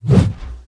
swing1.wav